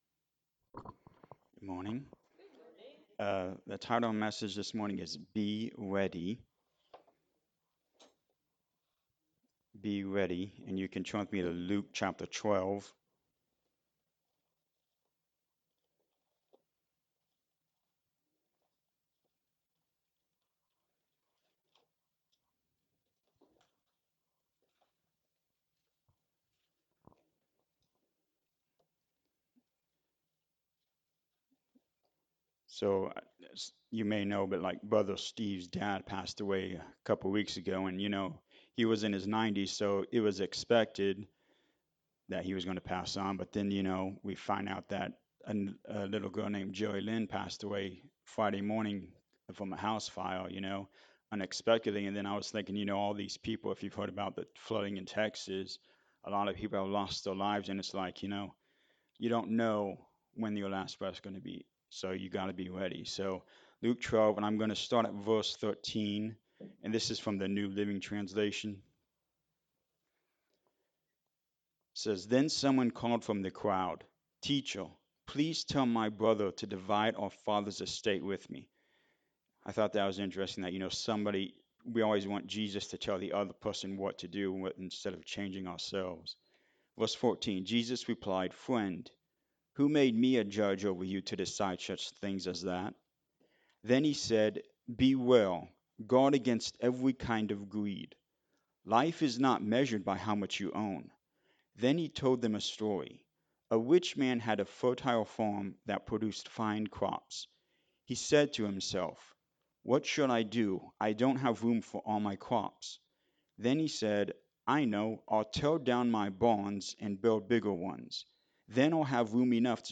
Luke 12:13-40 Service Type: Sunday Morning Service We never know when we will be leaving this earth.
Sunday-Sermon-for-July-6-2025.mp3